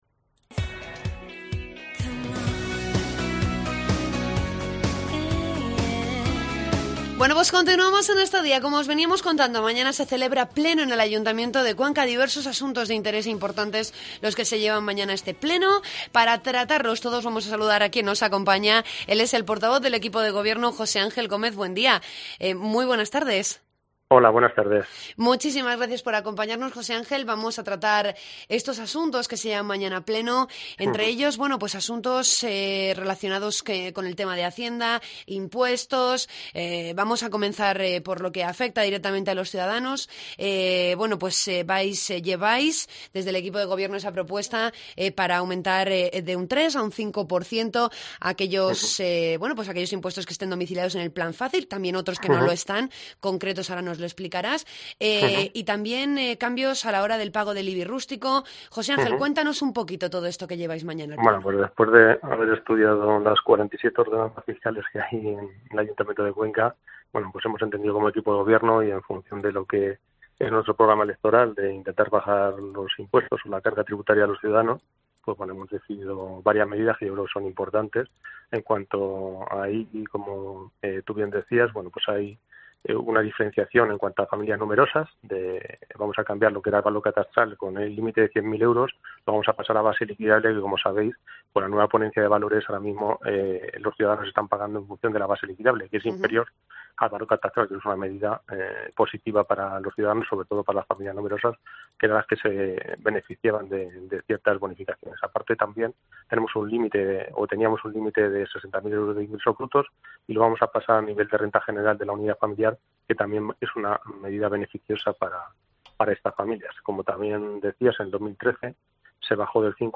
Entrevista al portavoz del Equipo de Gobierno del Ayuntamiento de Cuenca, José Ángel Gómez Buendía.